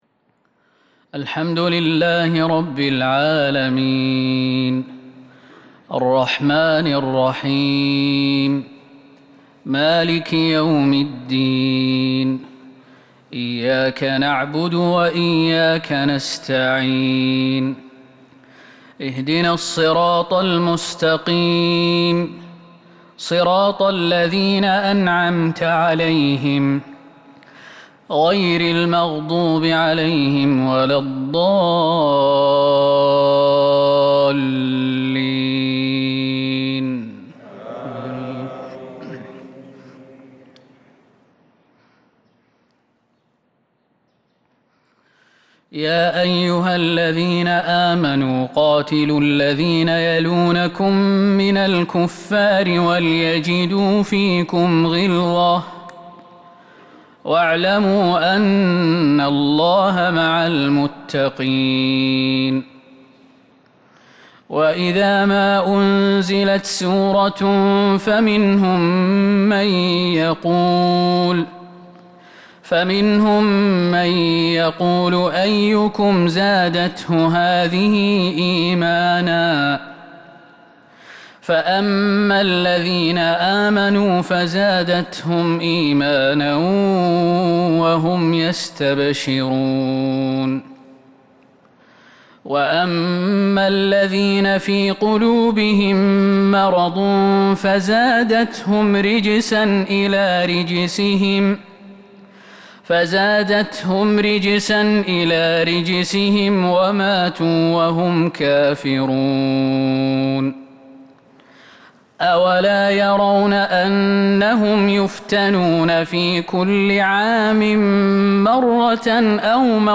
صلاة الفجر من سورتي التوبة والصف الجمعة ٦ ربيع الأول ١٤٤٢ه‍ـ | Fajr prayer from Surat At-taubah and Surat As-Saff 23/10/2020 > 1442 🕌 > الفروض - تلاوات الحرمين